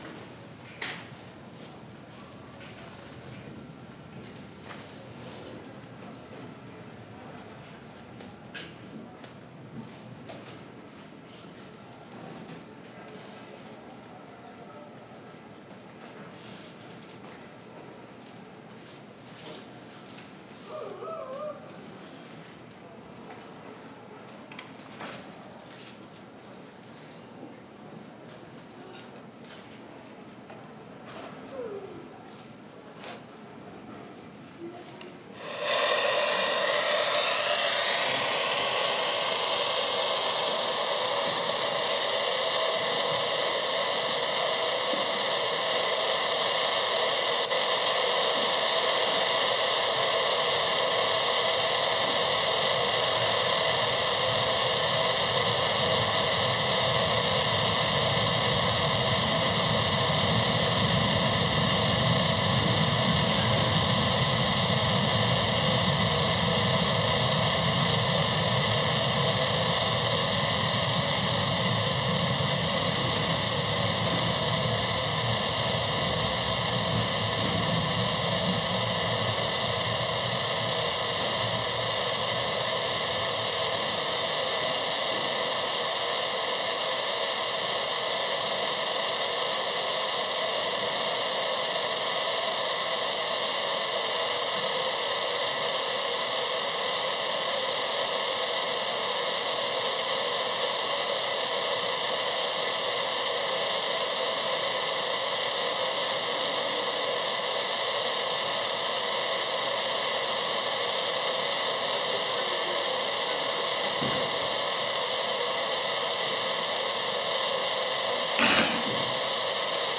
A saxophone: thought through, taken apart, rattled, blown through, a beer can vibrated in the horn, a chance signal from a clockwork radio vibrating the keys. Handheld fans. Shrill squeaks.
Venue The Arches, Glasgow
Improvising
Agricultural thrumming.